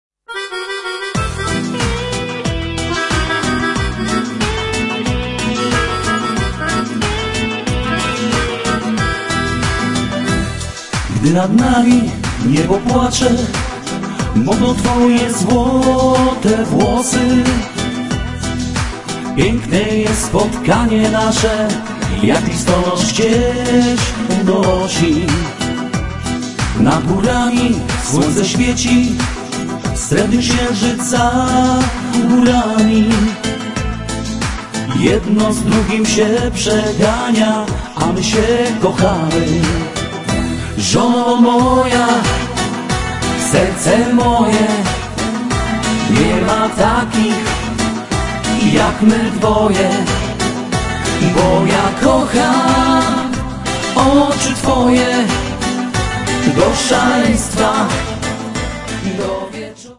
Vocals
Accordion
Percussion
Guitars